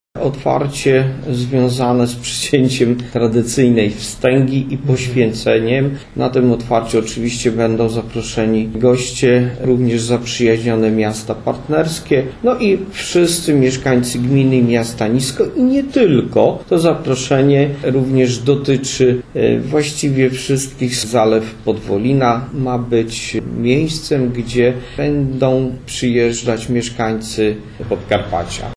Natomiast oficjalne otwarcie podsumowujące inwestycje jaką było zagospodarowanie terenu rekreacyjnego wokół zbiornika odbędzie się 11 lipca. Mówi burmistrz Niska Waldemar Ślusarczyk: